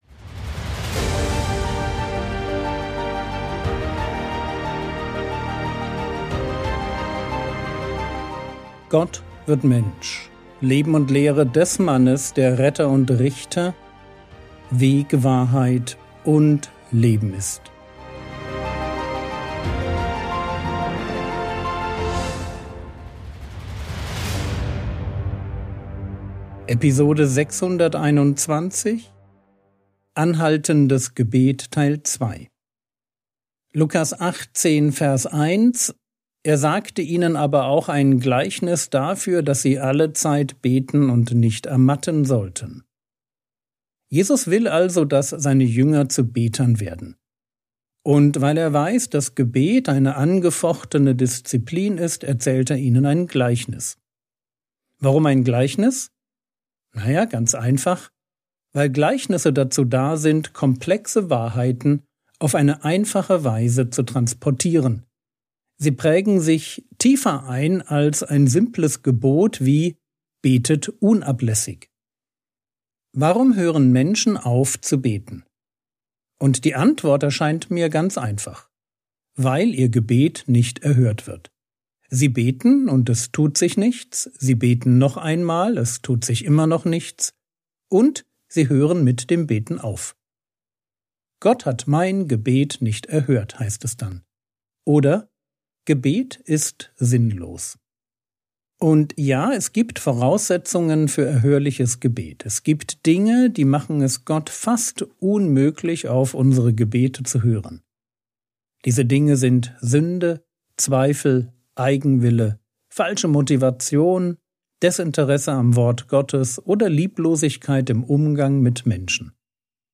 Episode 621 | Jesu Leben und Lehre ~ Frogwords Mini-Predigt Podcast